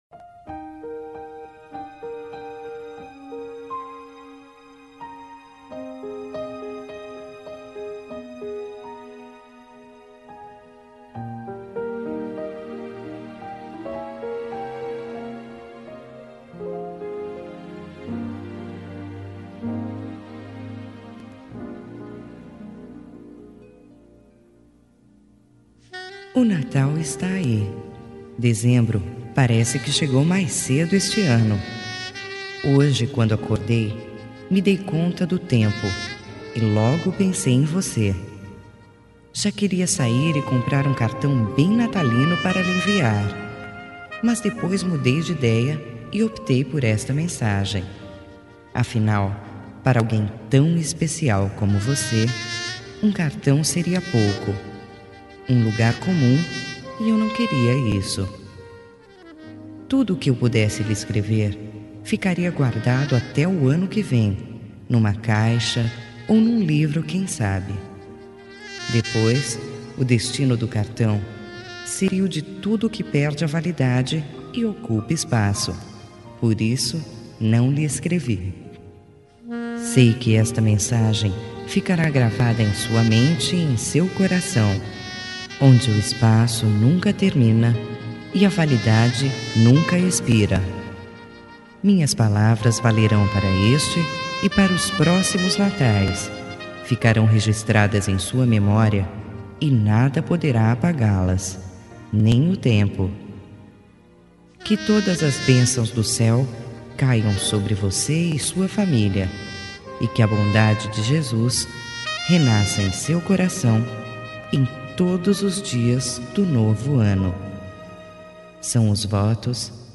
Feliz Natal Voz Feminina